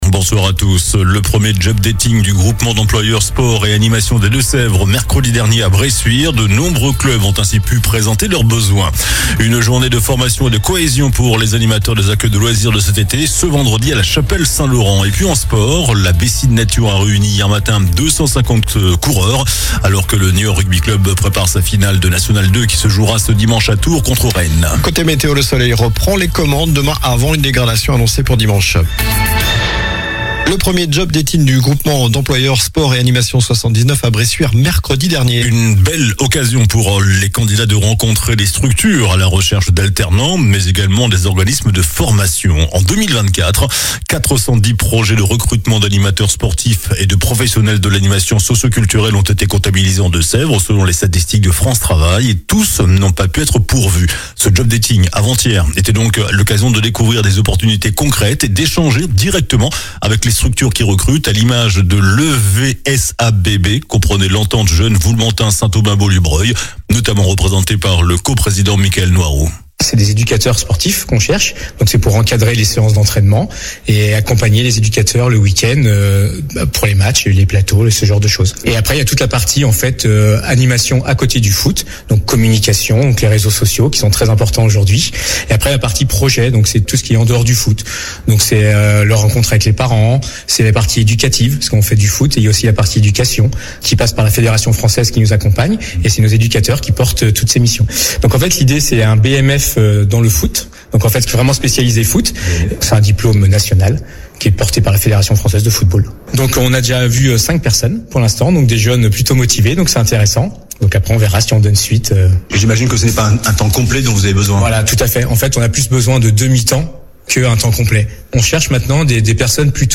JOURNAL DU VENDREDI 02 MAI ( SOIR )